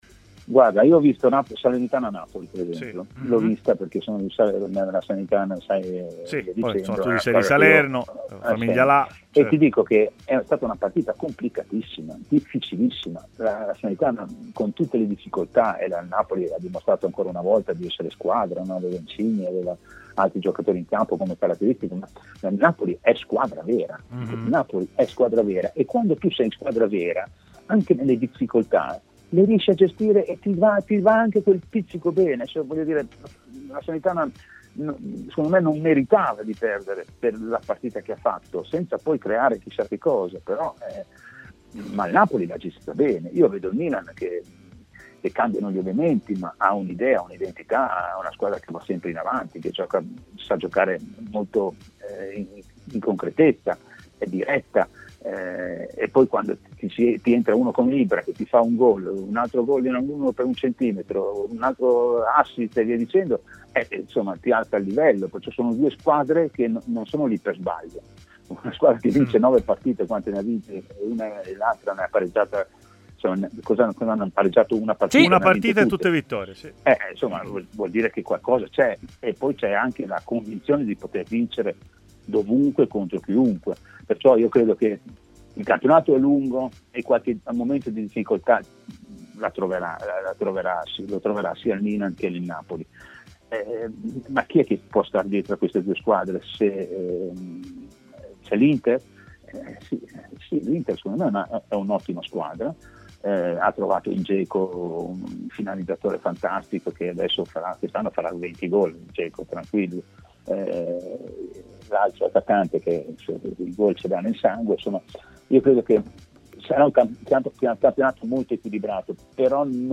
L'allenatore Giuseppe Galderisi è intervenuto a Stadio Aperto, trasmissione pomeridiana di TMW Radio, parlando di vari temi, a cominciare dalla Juventus: "Il risultato dice tanto.